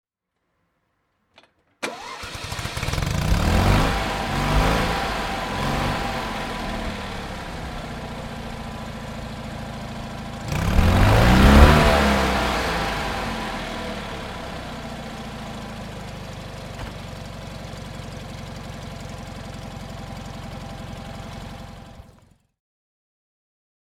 Fiat 500 L (1969) - Starten und Leerlauf